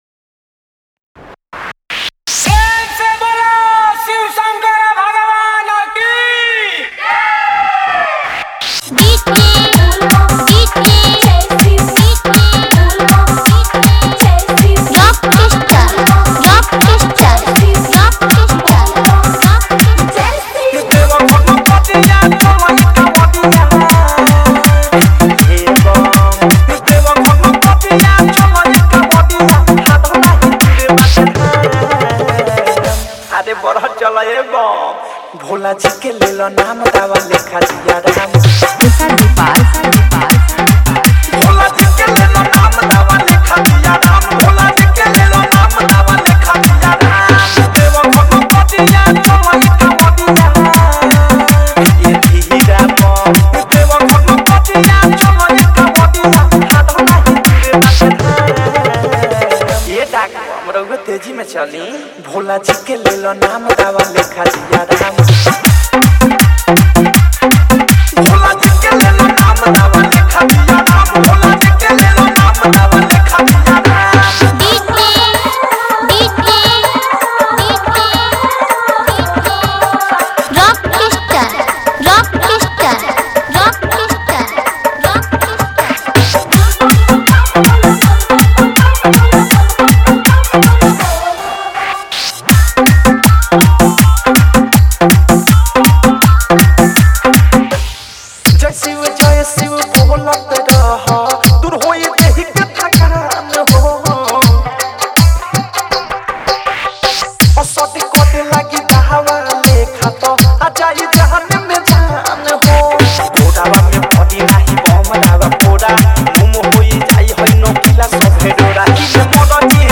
Category:  Bol Bam 2022 Dj Remix Songs